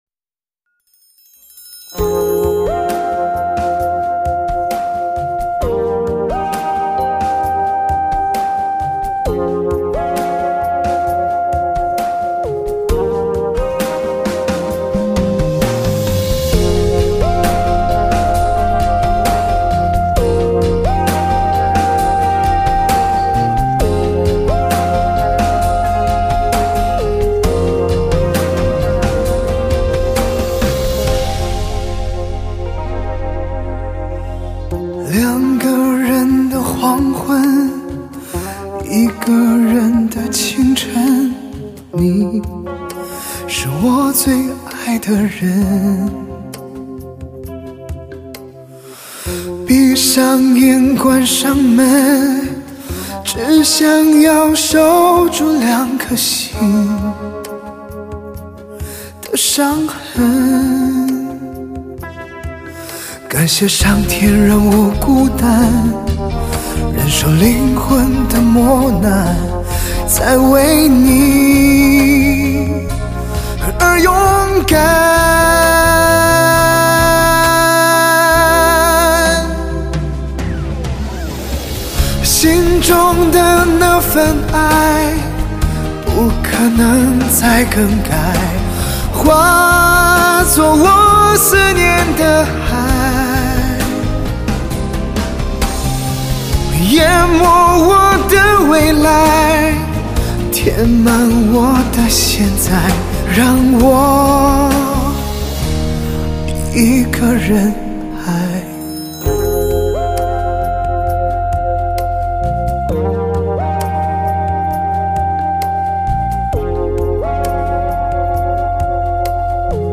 类型: HIFI试音